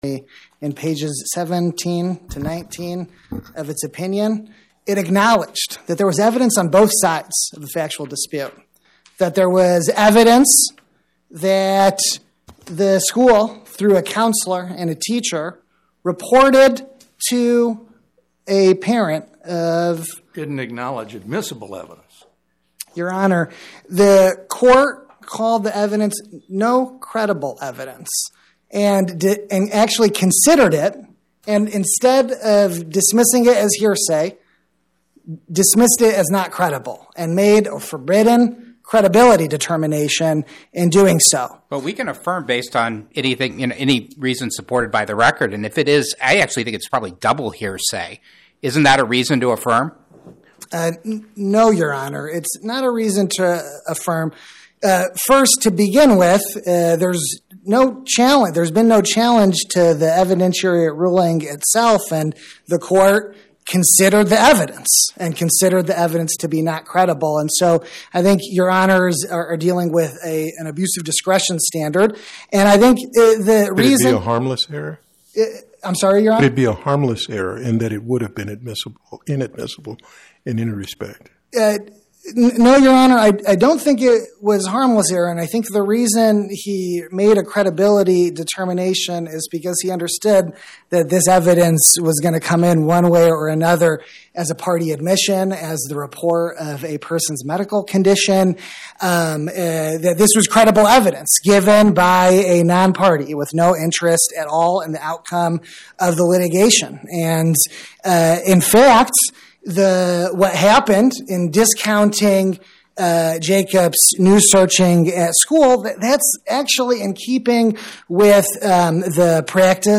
Oral argument argued before the Eighth Circuit U.S. Court of Appeals on or about 02/10/2026